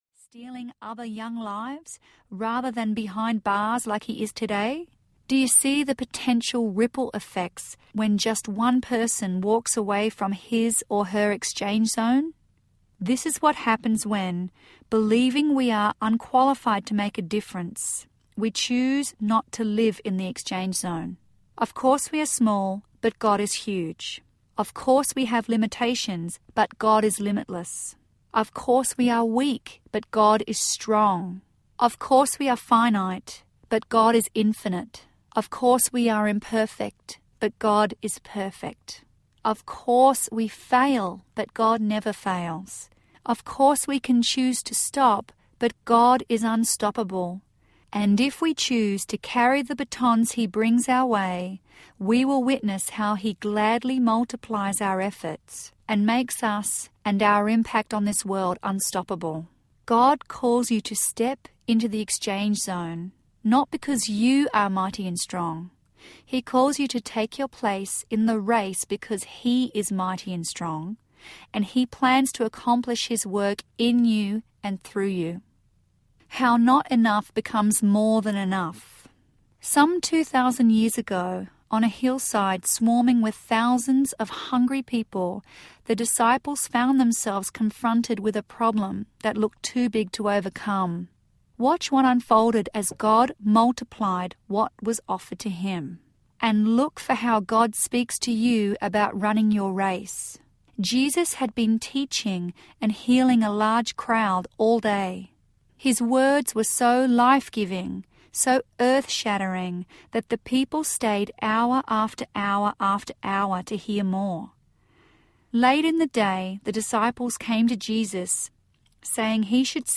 Unstoppable Audiobook
6.3 Hrs. – Unabridged